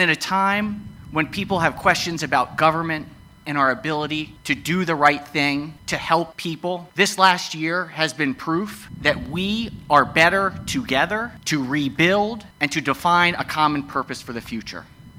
Maryland Senate President Bill Ferguson stepped away from the business of Annapolis on Wednesday to pay his respects at the first anniversary of the fall of the Francis Scott Key Bridge. Ferguson spoke alongside other state and federal leadership to remember victims and look ahead to a new bridge structure taking shape.